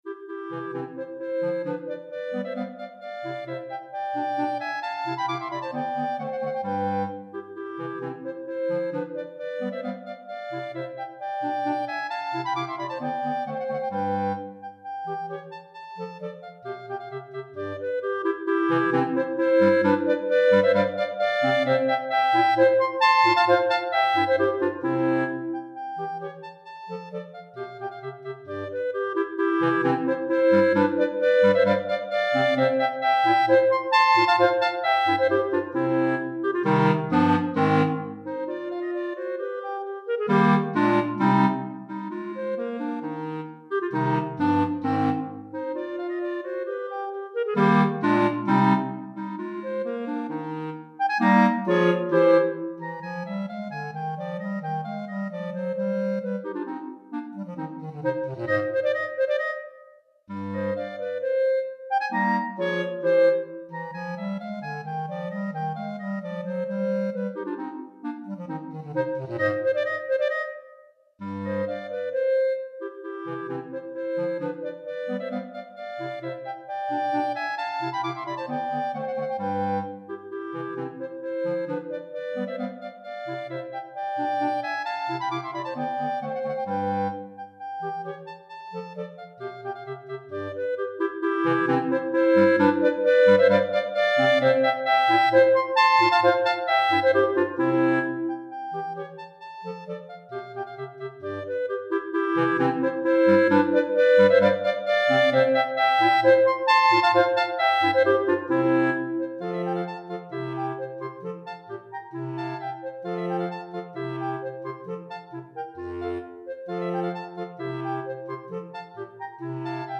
3 Clarinettes Sib et Clarinette Basse